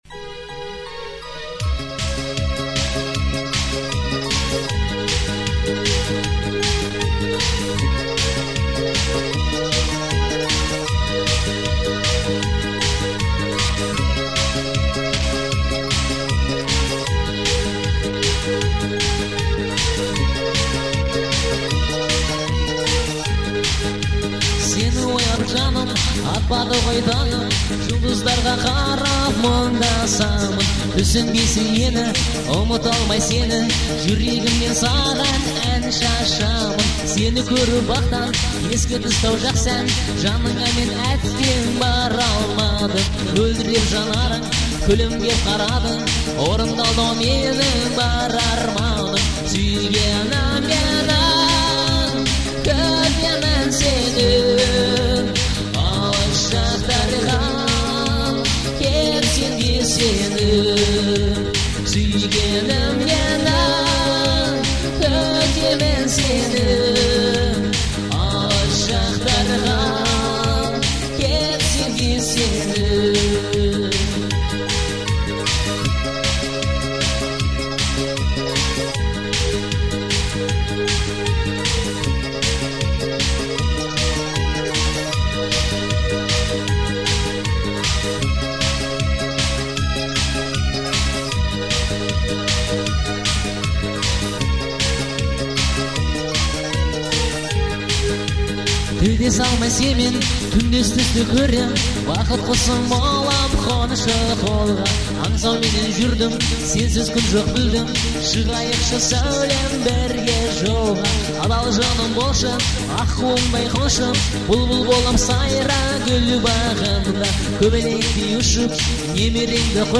отличается мелодичностью и гармонией